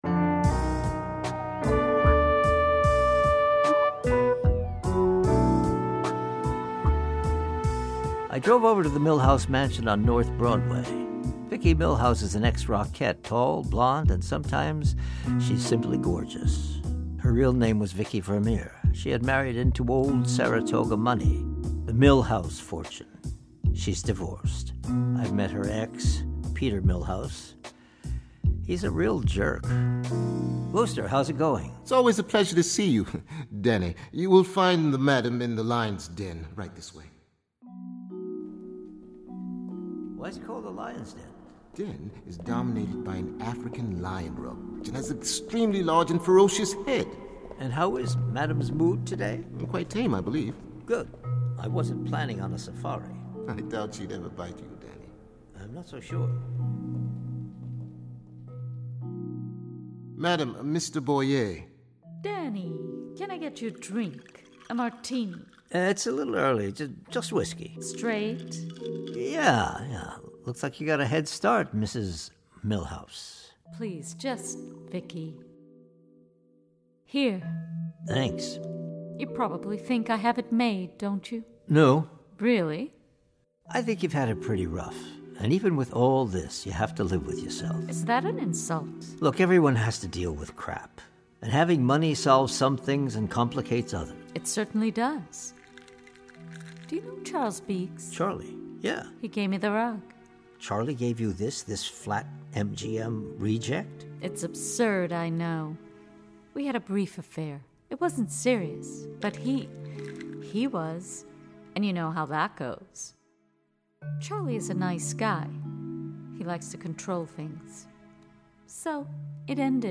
Here are the last chapters [15-20] of ZBS’s outrageous audio drama, “Saratoga Noir,” in which we finally learn if Danny Boyee, our fearless [and often, it seems, clueless] gumshoe is gonna stay out of Velma’s and Johnnie’s bars long enough to find that gol-danged cat. Or maybe find True Love with the sweet, high-spirited, named-after-the-orange Valencia?